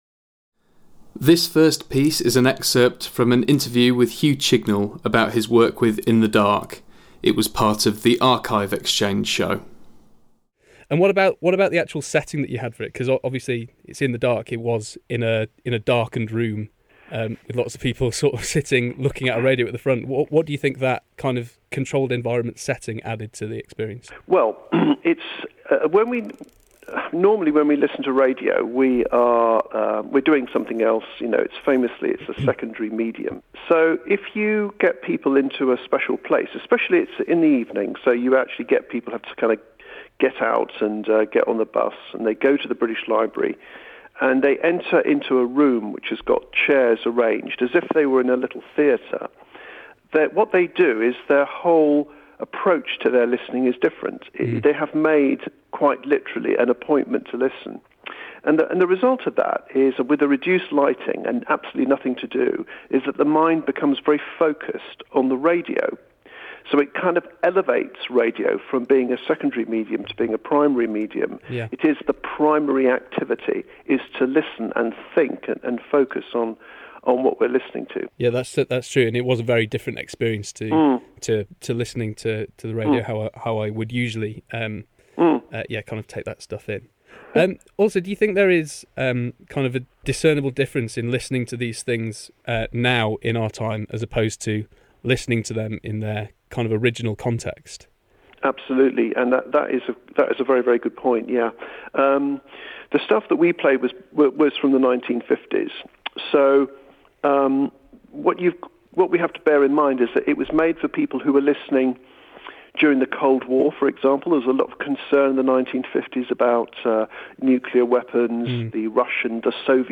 Genre: Speech/ Interview.
Genre: Experimental/ Sound Art.
Genre: Sketch Comedy.
Genre: Music/ Speech/ Feature.